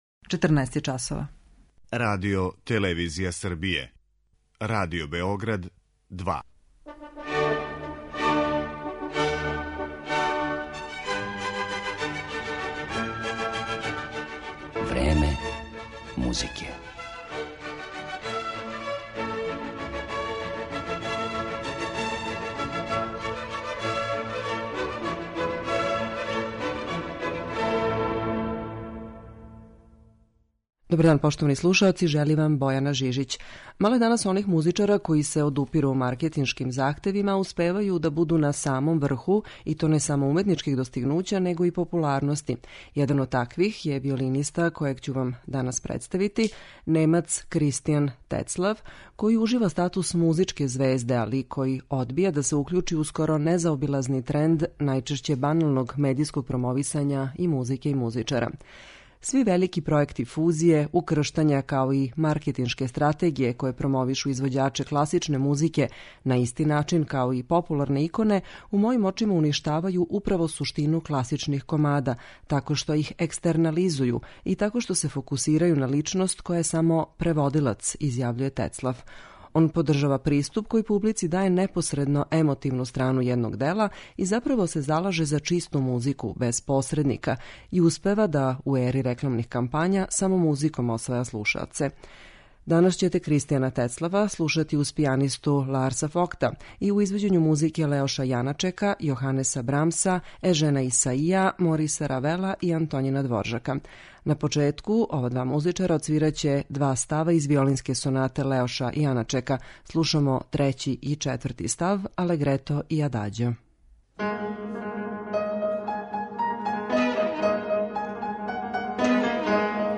Слушаћемо виолинисту Кристијана Тецлафа
Један од таквих је изузетни немачки виолински виртуоз Кристијан Тецлаф, који ће свирати у данашњој емисији.